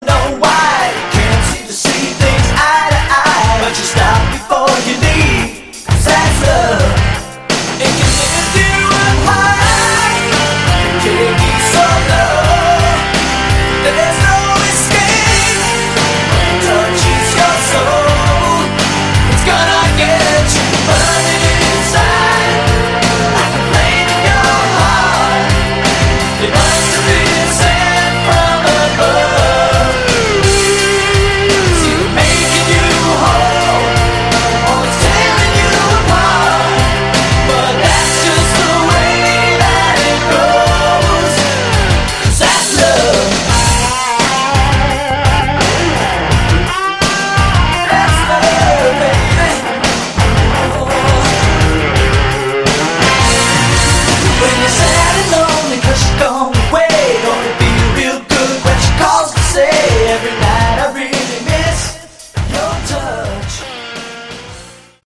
Category: AOR
Original demos.